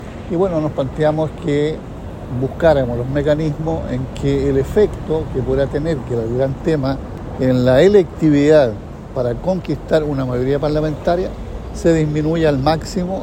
Así lo hizo, por ejemplo, el presidente del Partido Comunista, Lautaro Carmona.